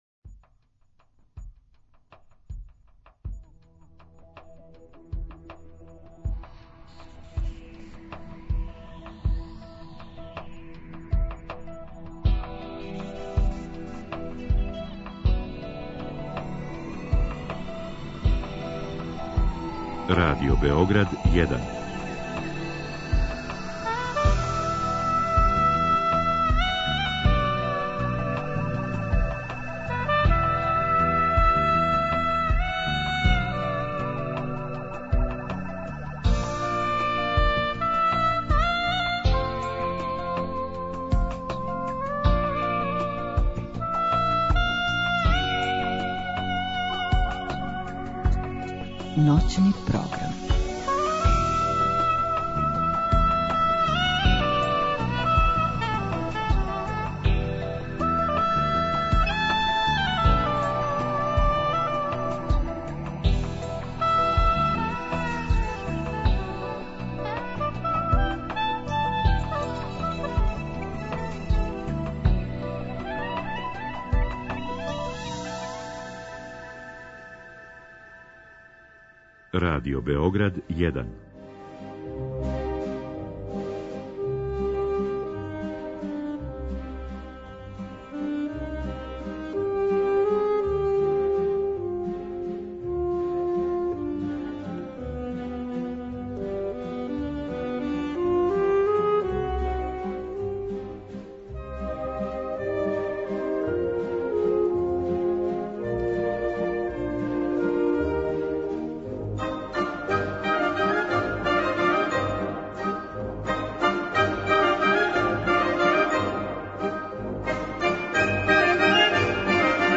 Први сат емисије која је посвећена уметничкој музици, обележиће звук хармонике и виоле, као и приказ концерта који је 7. октобра одржан у Студију 6 Радио Београда.
У другом сату слушаћете музику коју потписују или изводе превасходно уметници из Јужне Америке, док ће 3. и 4. сат испунити лагани ставови концертних и симфонијских остварења